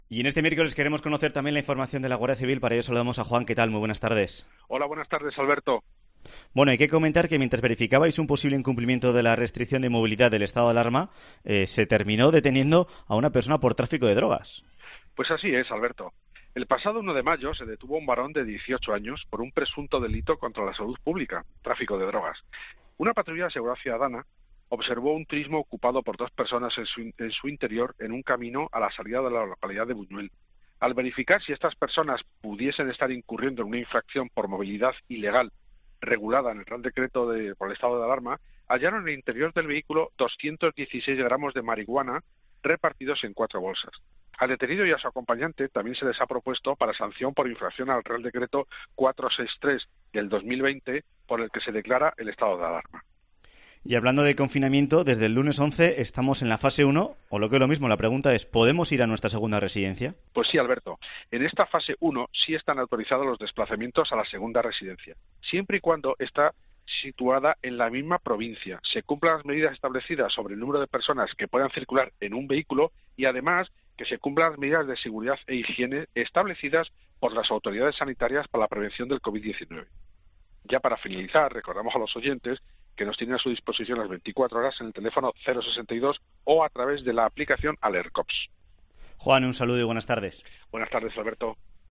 La Guardia Civil de Navarra ha explicado en Cope Navarra las novedades que hay en la Comunidad con foral con la entrada de la fase 1 en cuanto a la movilidad de vehículos: